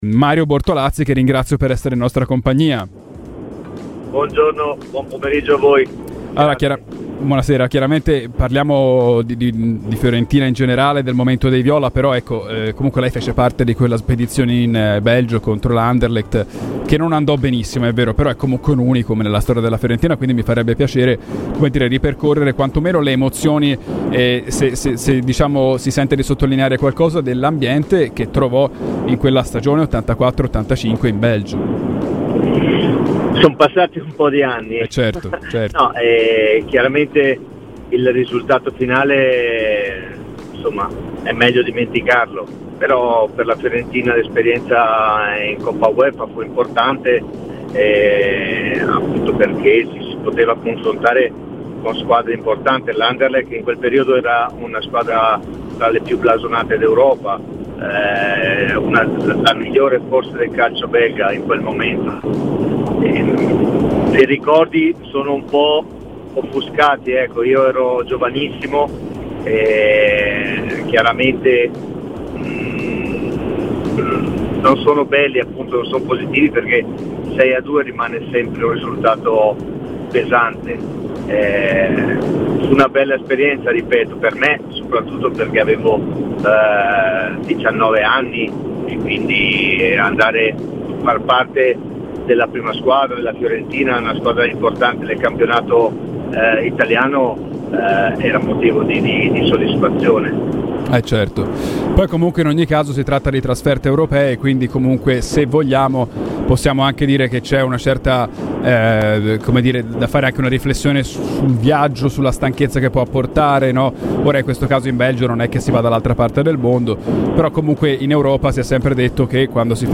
Mario Bortolazzi, ex viola e protagonista dell'unica trasferta in Belgio della storia della Fiorentina, è intervenuto a Radio FirenzeViola per parlare di quell'unico precedente (pesante sconfitta contro l'Anderlecht nella Coppa Uefa 1984-85) : "Il 6-2 di quella volta fu pesante, ma la trasferta me la ricordo positivamente perché comunque avevo meno di venti anni e far parte di una trasferta europea a quell'età mi fece molto piacere".